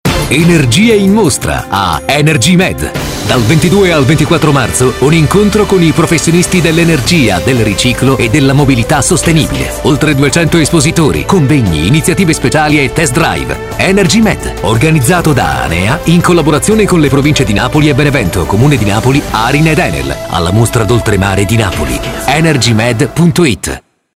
Spot EnergyMed (MP3 376KB)